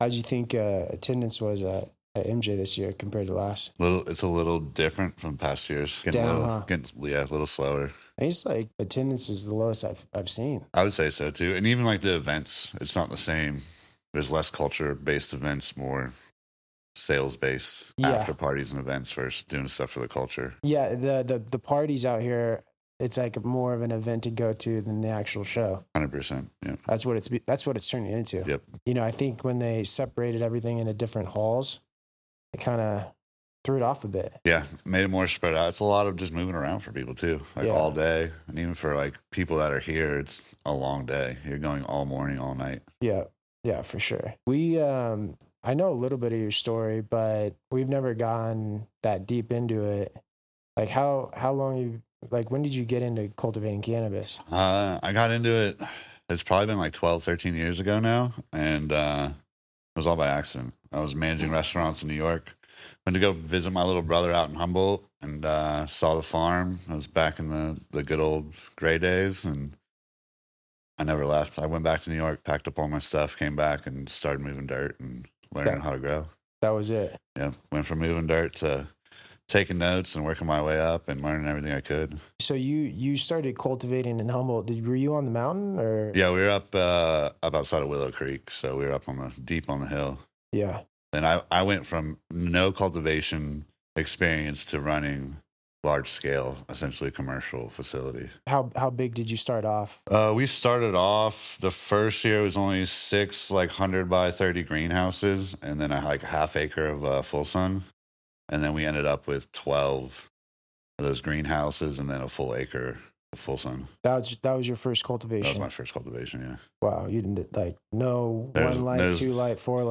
A Growers podcast hosted by a panel of growers based out of Southern California. We bring you perspective, best practices and discuss the latest technology in the Cannabis Industry.